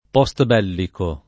vai all'elenco alfabetico delle voci ingrandisci il carattere 100% rimpicciolisci il carattere stampa invia tramite posta elettronica codividi su Facebook postbellico [ p qS t b $ lliko ] (raro posbellico ) agg.; pl. m. ‑ci